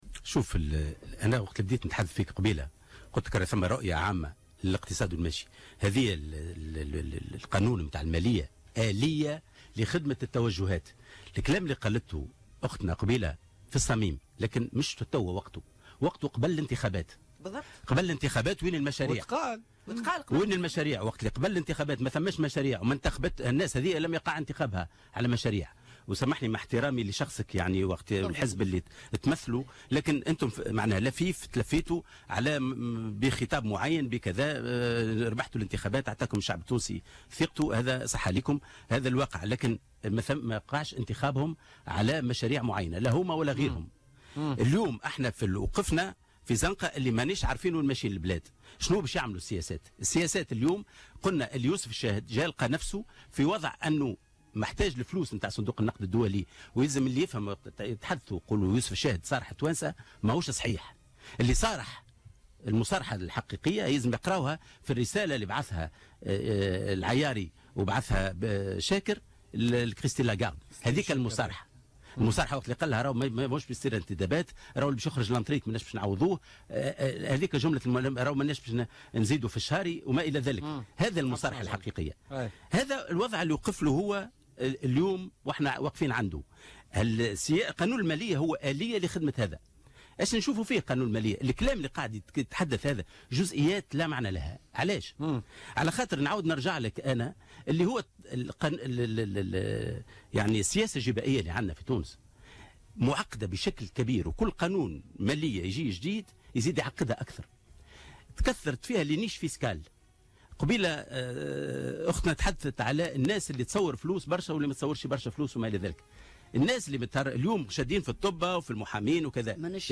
قال لطفي المرايحي الأمين العام لحزب الاتحاد الشعبي الجمهوري ضيف برنامج بوليتكا لليوم الخميس 17 نوفمبر 2016 إن أصحاب المهن الحرة يجب أن يدفعوا الضرائب ولكن المتهربين الحقيقيين جبائيا اليوم هم أصحاب الثروات والذين يتهربون بقوة القانون وبالامتيازات الجبائية الموجودة والاستثناءات وهو المسكوت عنه وفق تعبيره.